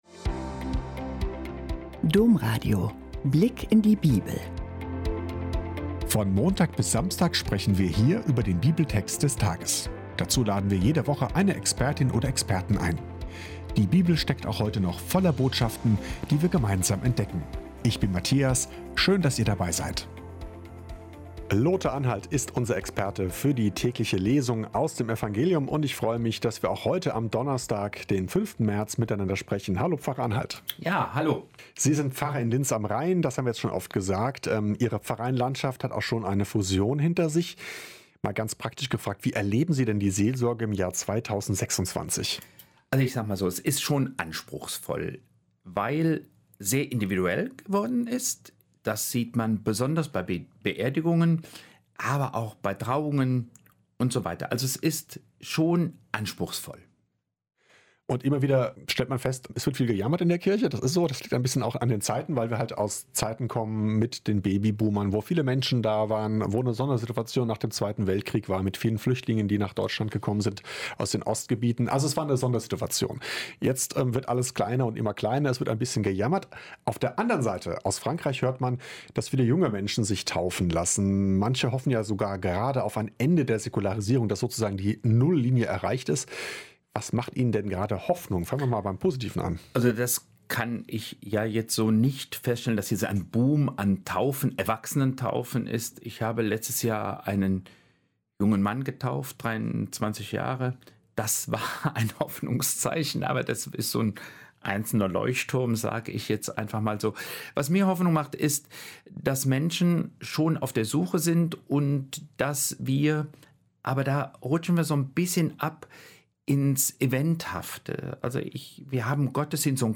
"Reichtum rettet nicht“ - Gespräch